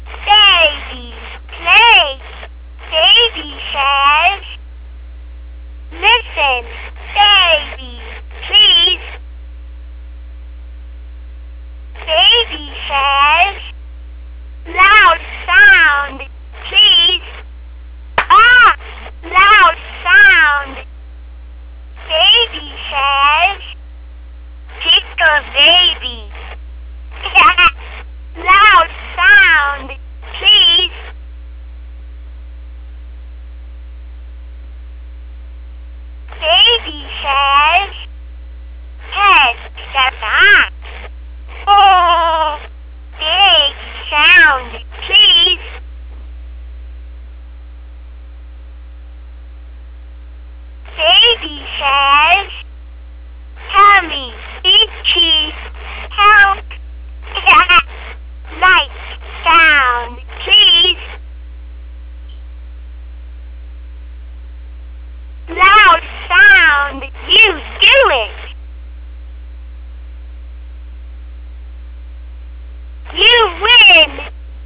I've put together some sounds of my Furby Babies so you can hear the differances between adult Furby and Baby Furby.
-Wee-Tee and me are playing a game of Furby Baby Says